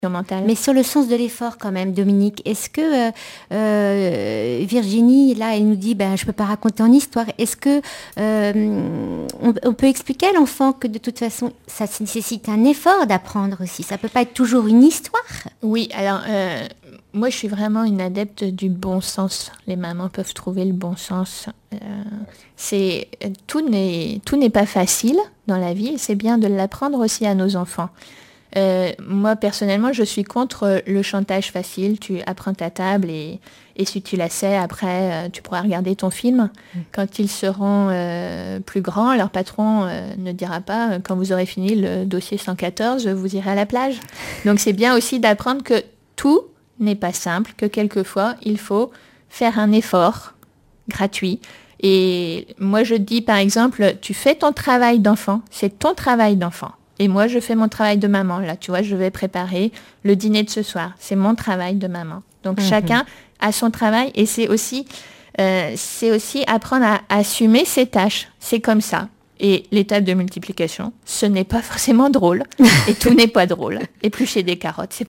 Emissions de la radio RCF Vendée
témoignages sur l'accompagnement scolaire
Catégorie Témoignage